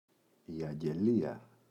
αγγελία, η [aŋge’lia]